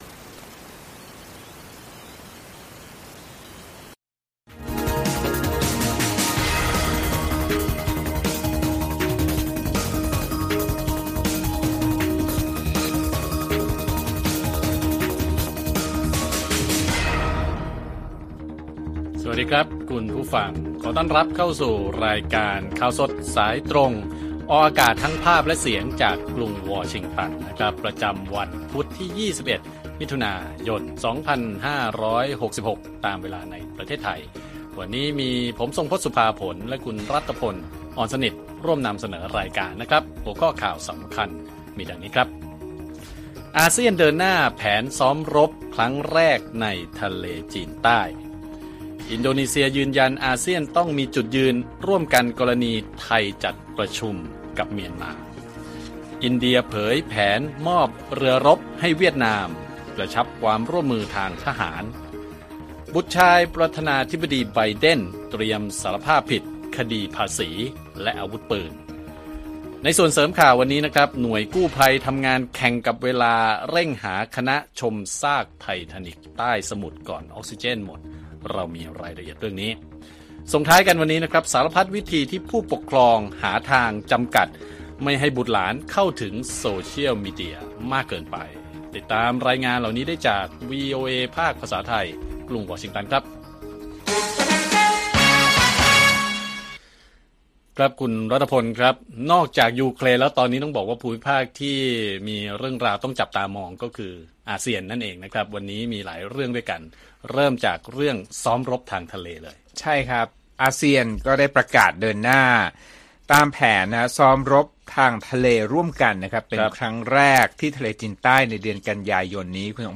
ข่าวสดสายตรงจากวีโอเอไทย 6:30 – 7:00 น. วันที่ 21 มิ.ย. 2566